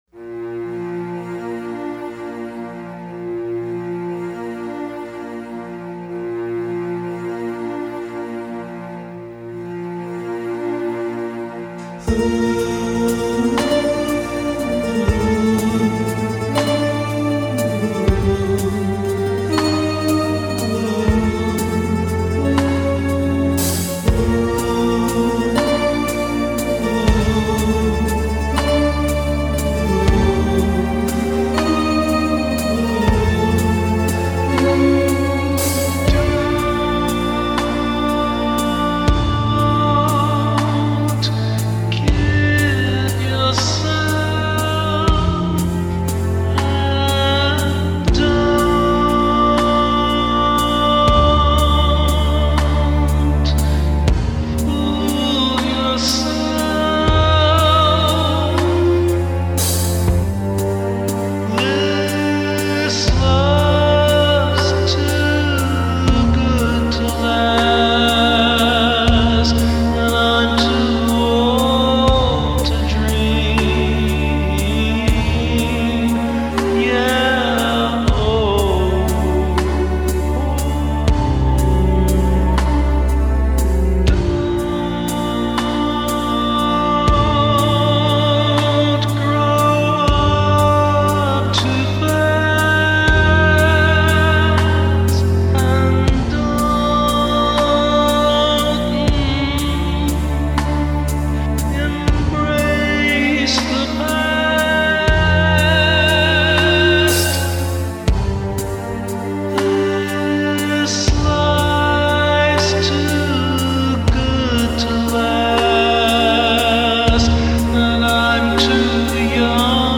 Progressive covers used to learn multitrack recording